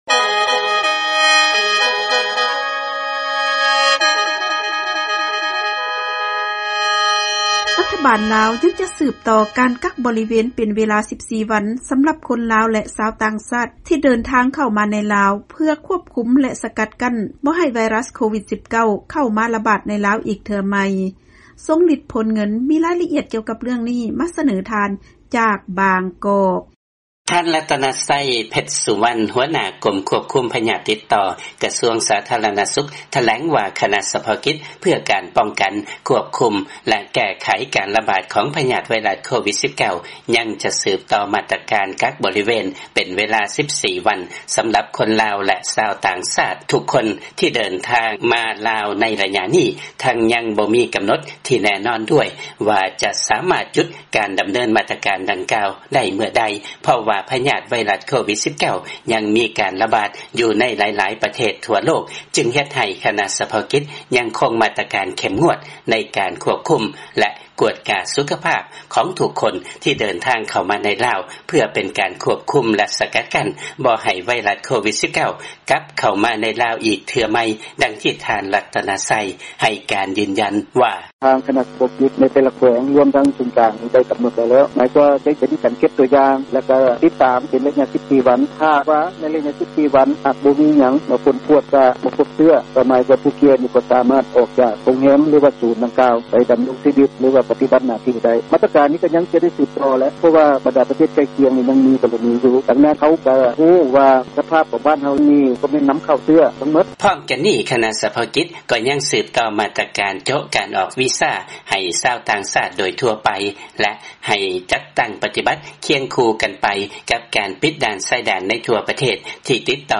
ເຊີນຟັງລາຍງານ ທາງການລາວ ຍັງຈະສືບຕໍ່ ການກັກບໍລິເວນ 14 ວັນ ສຳລັບຄົນລາວ ແລະຊາວຕ່າງຊາດ ທີ່ເດີນທາງເຂົ້າມາໃນລາວ